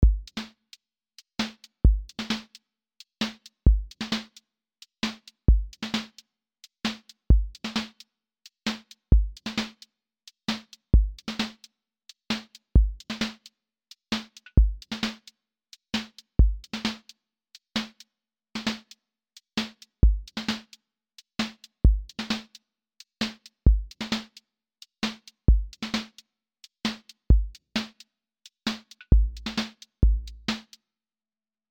QA Listening Test contemporary-rap Template: contemporary_rap_808
808-led contemporary rap beat with wide vocal space, moody melody loop, and a clean verse-to-hook lift